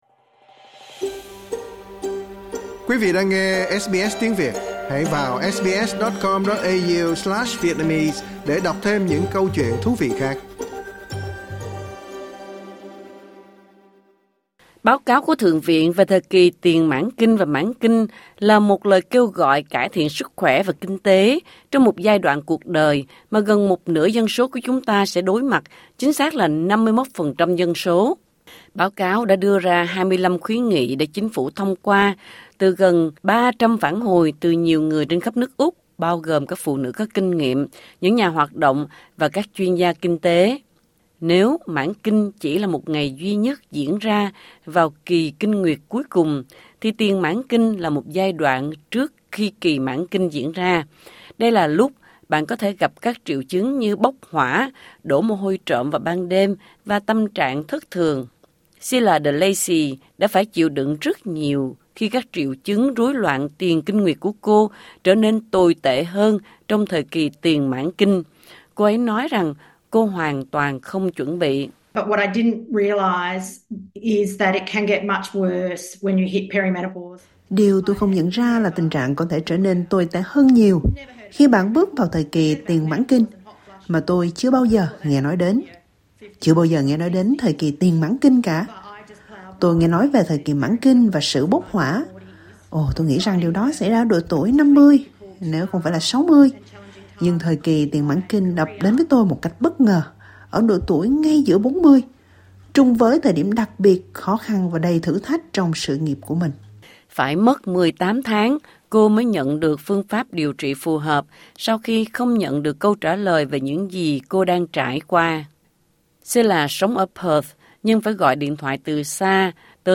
Trong phần này của loạt phóng sự Hysterical, chúng ta sẽ lắng nghe những người ủng hộ và phụ nữ chia sẻ về những gì họ muốn chính phủ liên bang thực hiện.